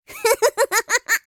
Teemo Laugh - Bouton d'effet sonore